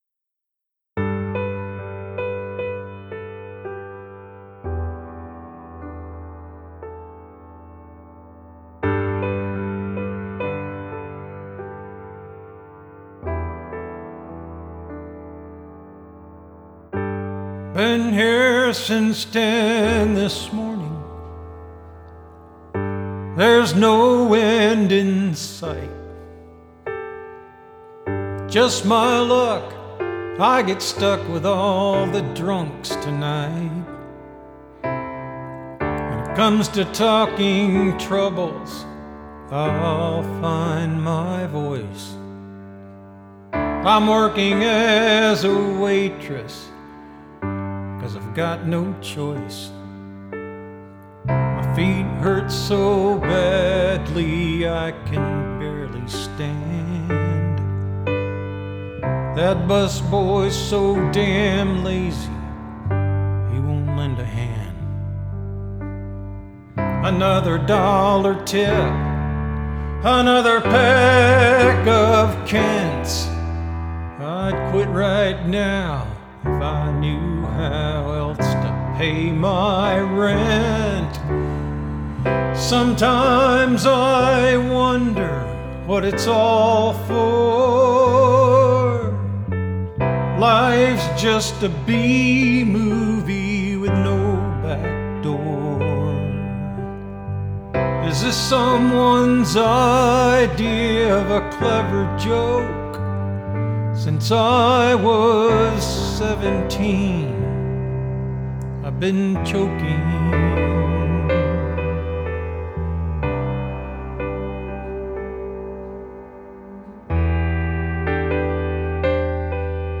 Home All songs created using Band-In-A-Box from PGMusic